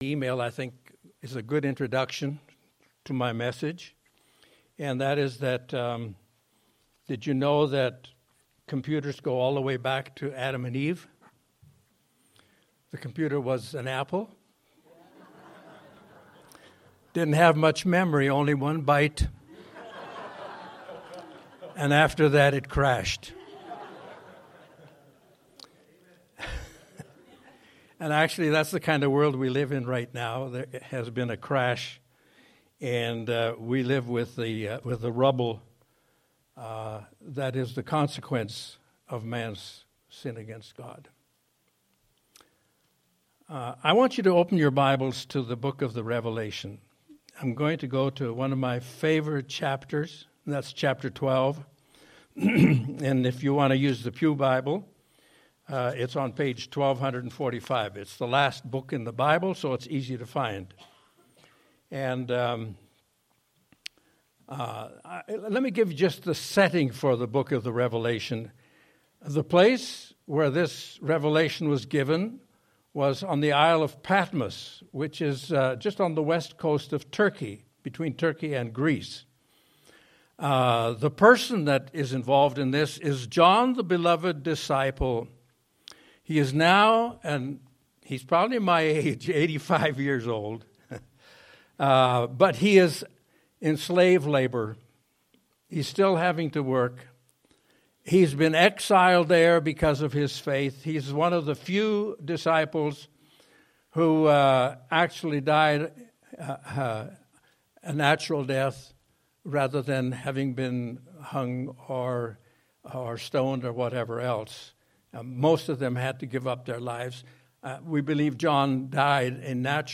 Series: Sunday Sermons